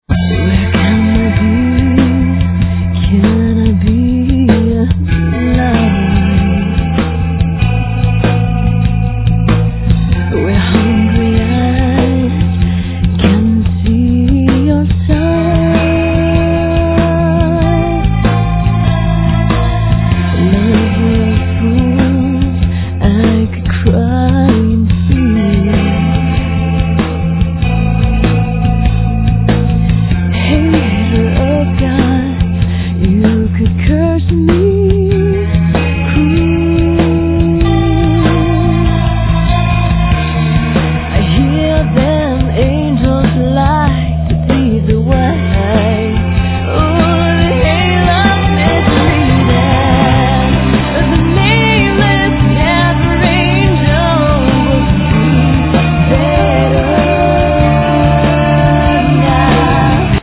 Doumbek, Tar, Riq, Congas, Shakes, Bells
Guitars, Synthes, Piano, Loop, Textures
Vocals
Drum, Loops, Samples
Fender Bass, Synthes, Piano, Shruti box
Trumpet
Flute
Violin
Didgeridoo